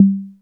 Index of /musicradar/essential-drumkit-samples/Vintage Drumbox Kit
Vintage Perc 02.wav